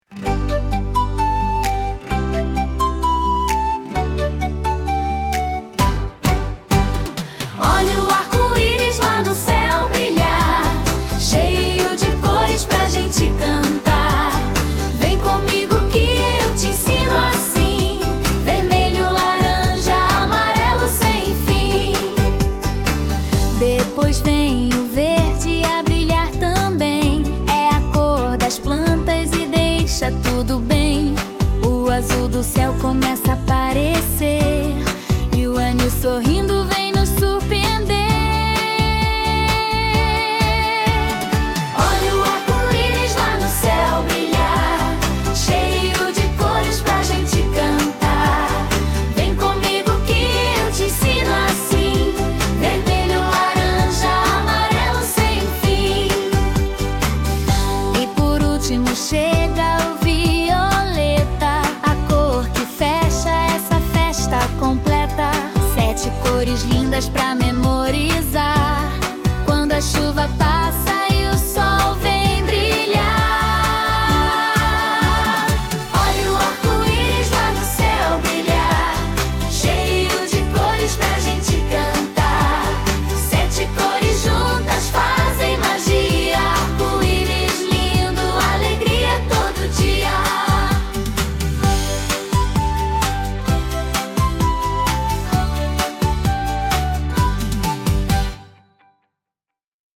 EstiloInfantil